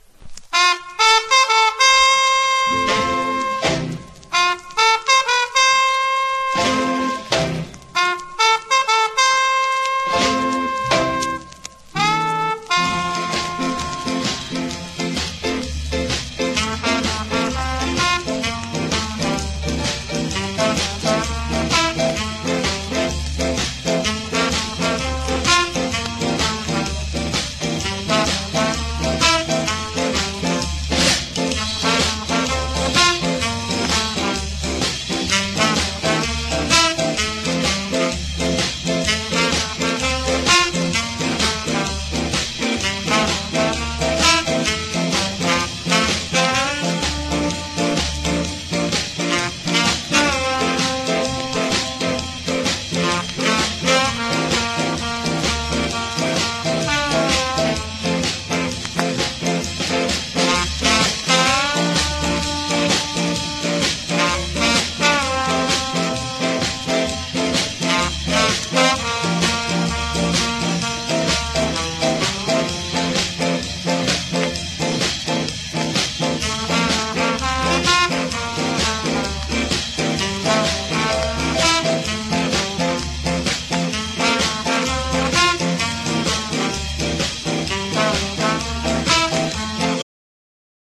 # ROOTS# SKA / ROCK STEADY